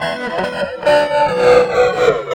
47 GUIT 1 -L.wav